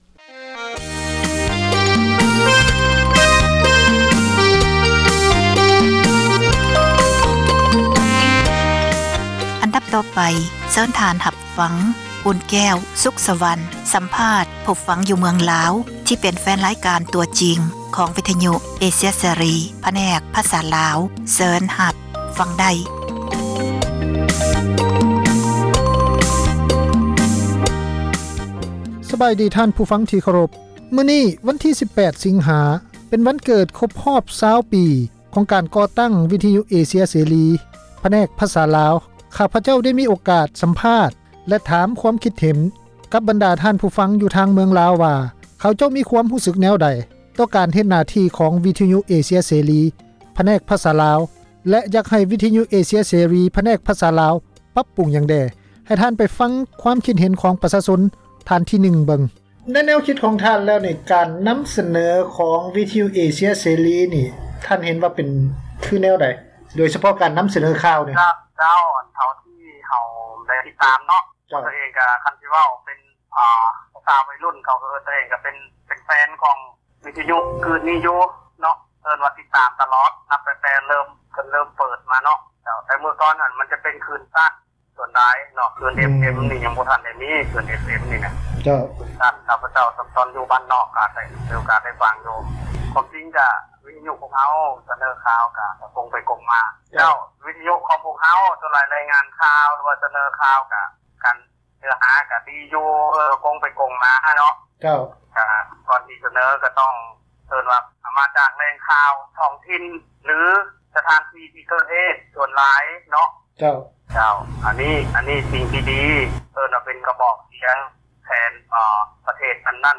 ສົນທະນາ ກັບທ່ານຜູ້ຟັງ ທີ່ເປັນແຟນຣາຍການ ຂອງວິທະຍຸ ເອເຊັຽເສຣີ ຜແນກພາສາລາວ